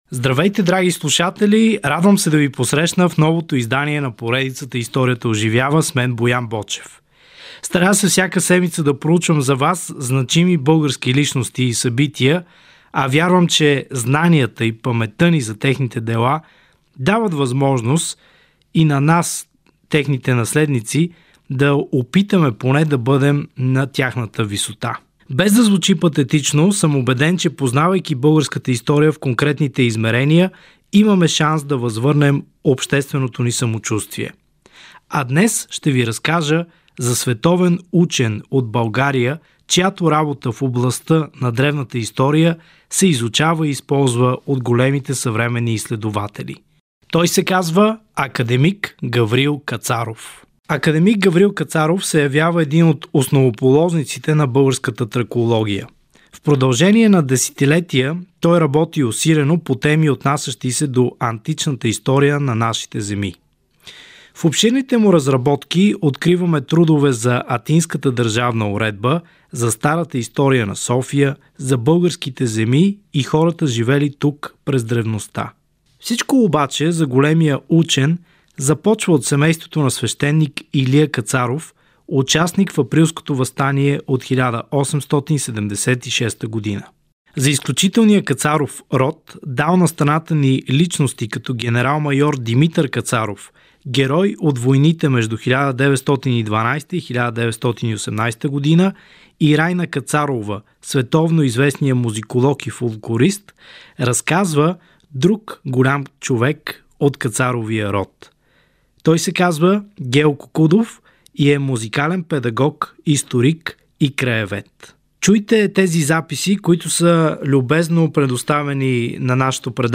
По този повод Дирекция на музеите в град Копривщица организира честване, записи от което ви очакват в епизода.